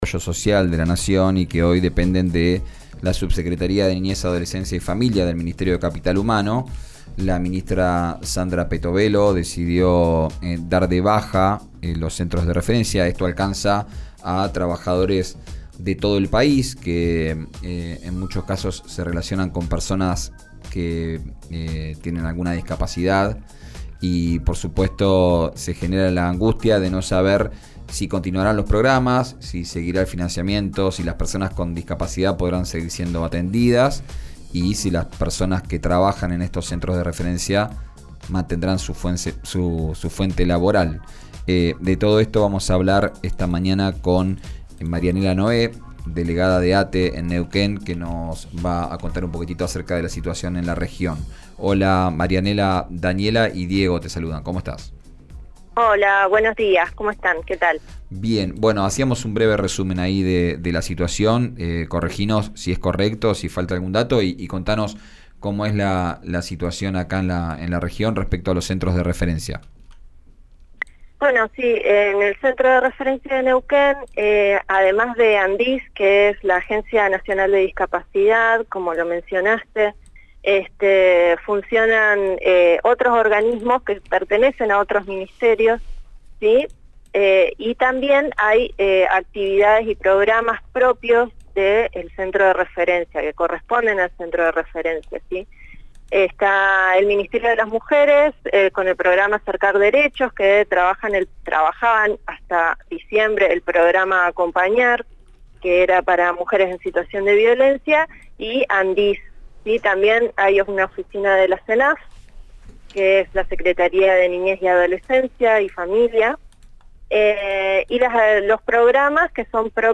En RÍO NEGRO RADIO hablaron de las consecuencias del cierre y aseguraron que los programas están frenados desde diciembre y no dan respuestas. Por esto, las provincias planean protestas y movilizaciones.